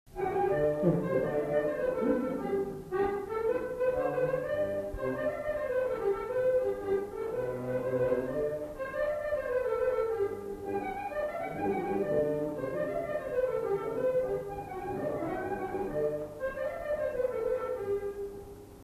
Chants et airs à danser
enquêtes sonores
Rondeau